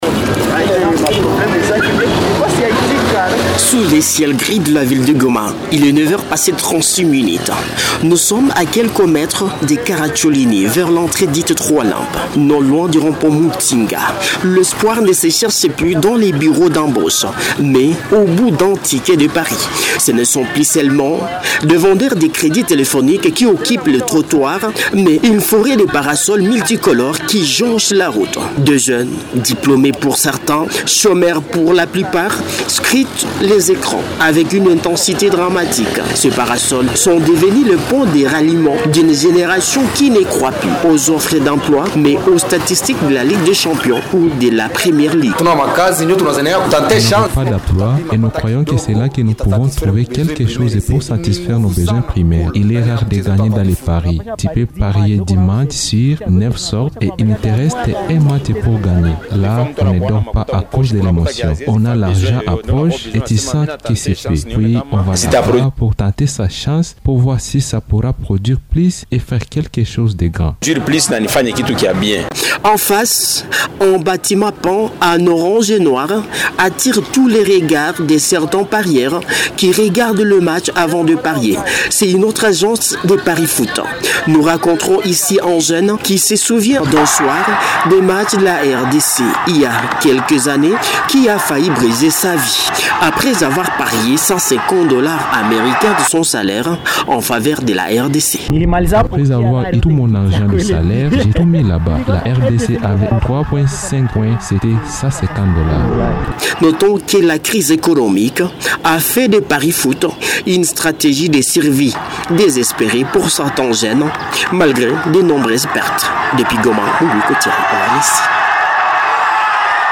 FR-REPORTAGE-PARIFOOT-12-FEVRIER.mp3